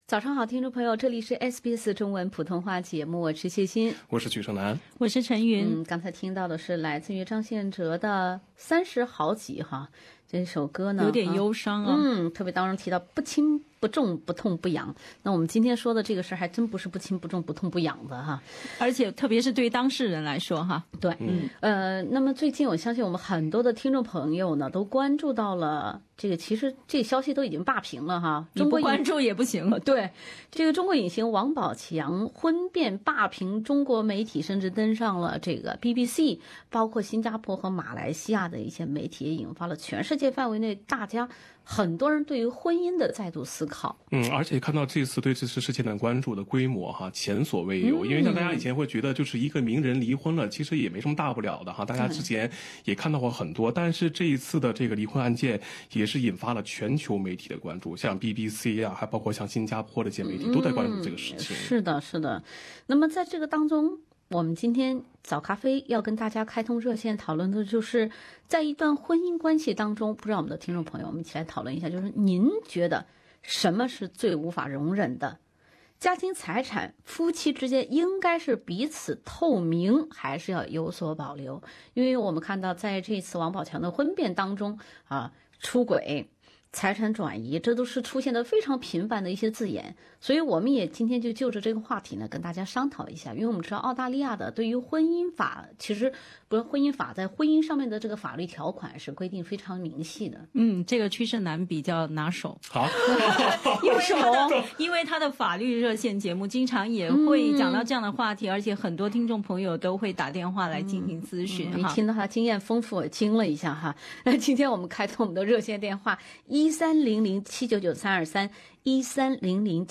本期《早咖啡》，听众分享对该事件，以及对婚姻状态、婚内财产的看法。